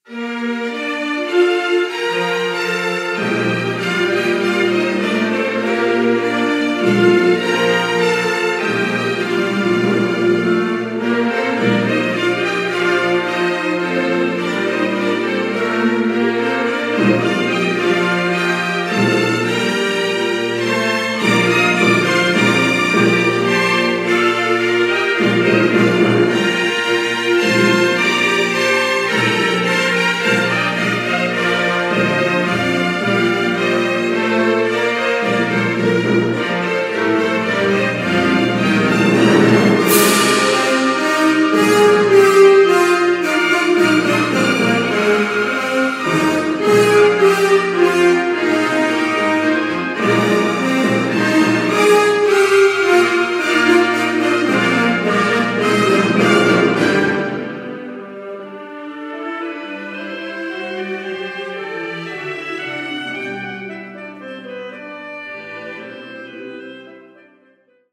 Sintonia de le emissions en llengua catalana